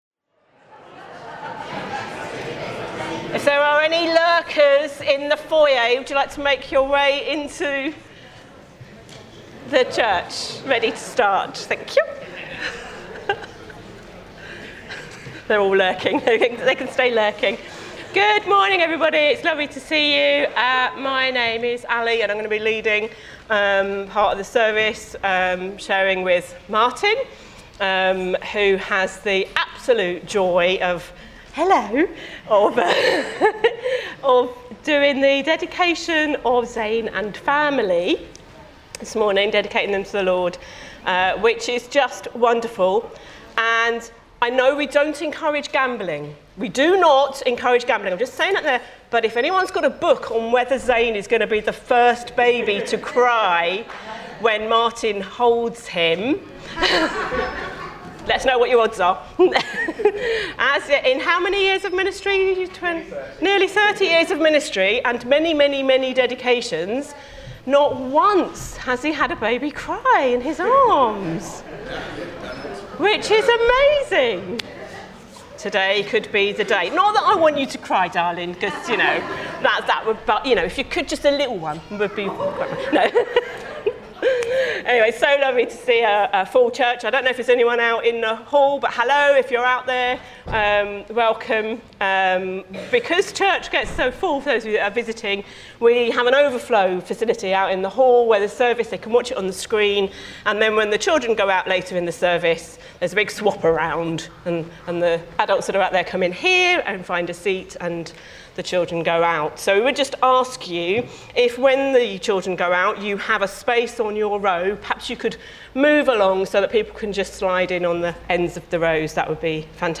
15 February 2026 – Morning Service